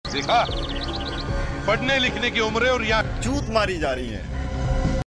ch t maari ja rahi hai Meme Sound Effect
This sound is perfect for adding humor, surprise, or dramatic timing to your content.